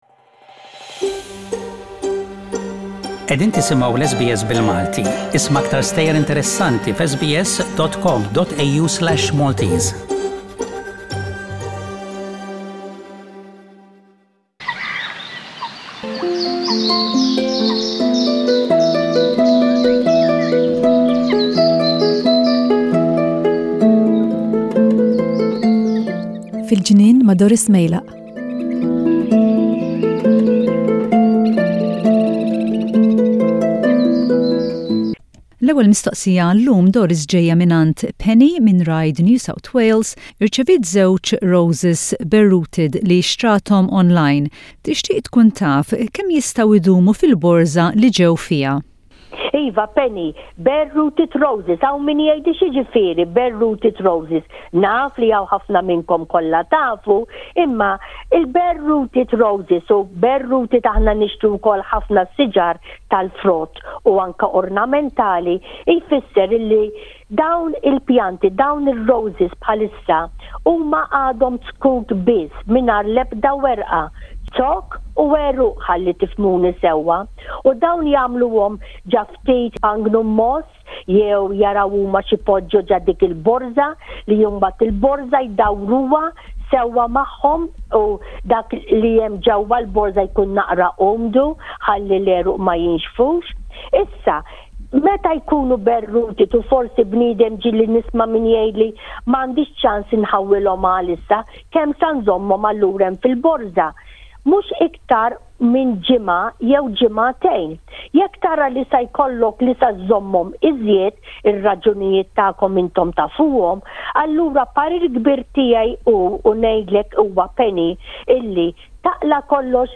Gardening | questions and answers